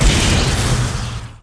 pabub_explo.wav